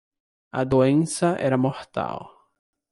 Read more Adj Noun Opposite of imortal Frequency B2 Pronounced as (IPA) /moʁˈtaw/ Etymology From Old Galician-Portuguese mortal, and their source Latin mortālis, from mors (“death”).